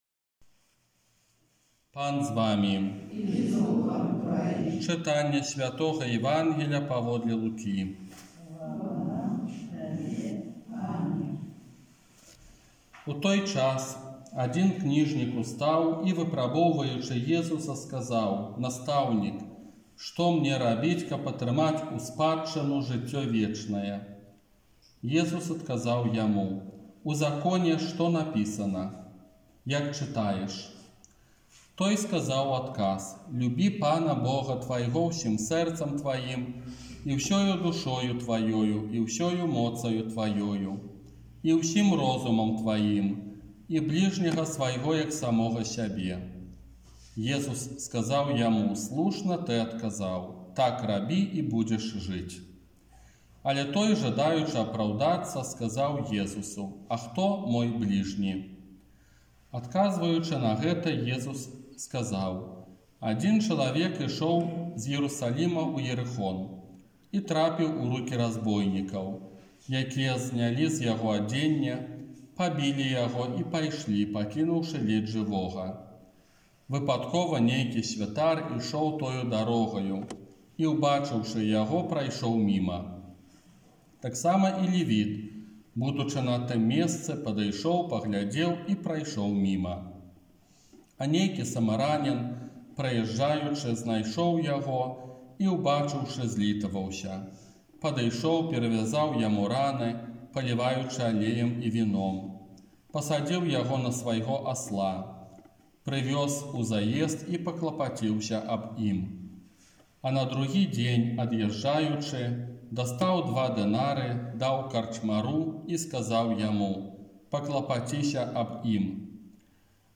ОРША - ПАРАФІЯ СВЯТОГА ЯЗЭПА
Казанне на пятнаццатую звычайную нядзелю